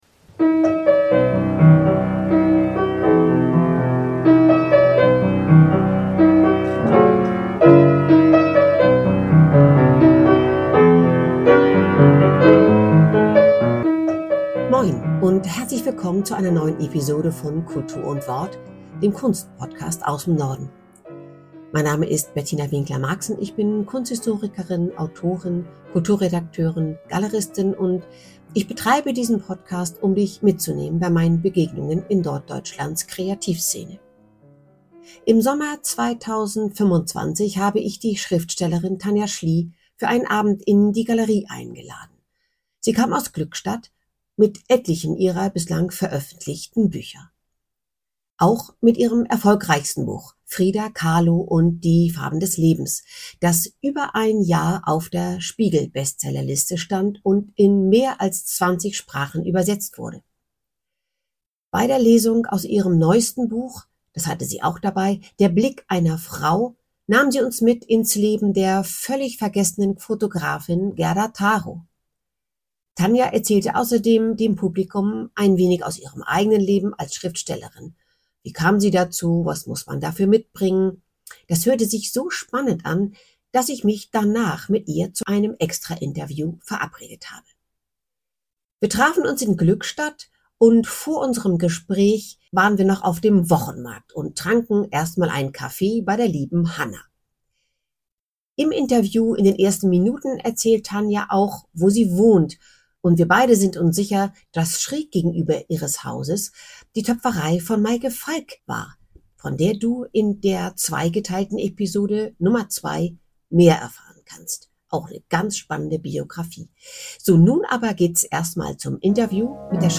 Das hörte sich so spannend an, dass ich mich danach mit ihr zu einem Interview verabredet habe.